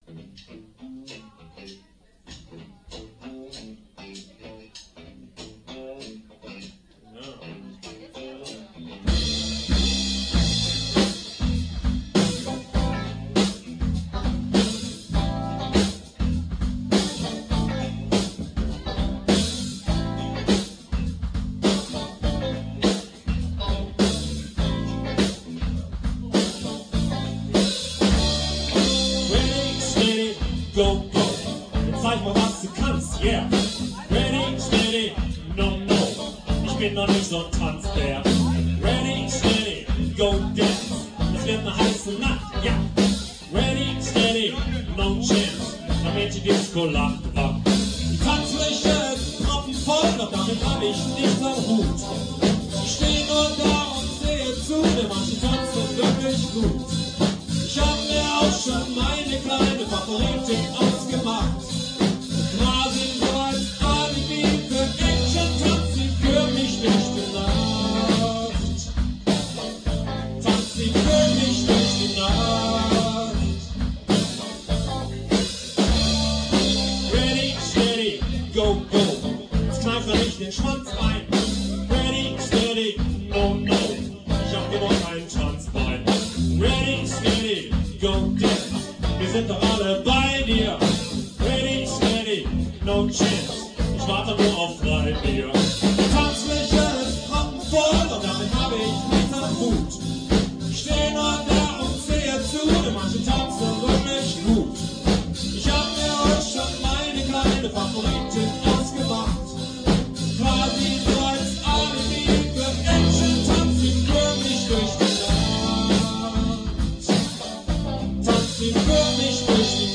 Gesang, Gitarre
Bass
Sologitarre
Schlagzeug